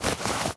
foley_object_push_pull_move_06.wav